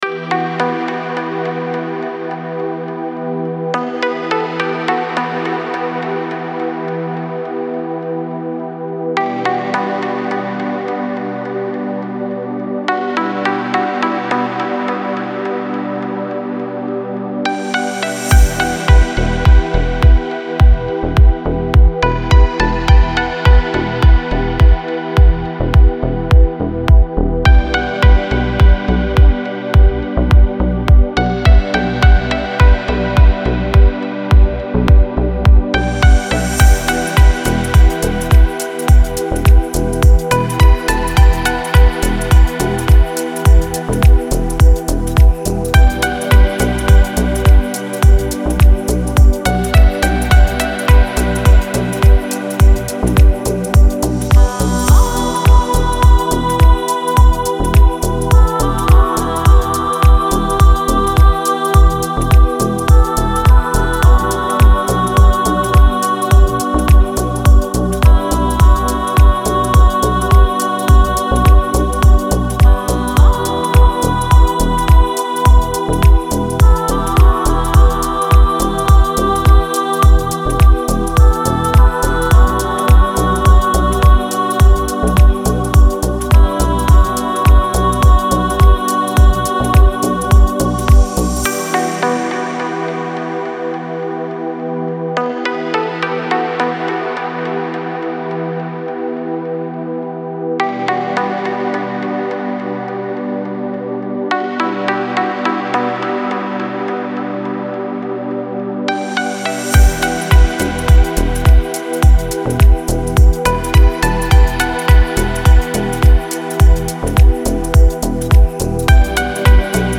موسیقی بی کلام دیپ هاوس ریتمیک آرام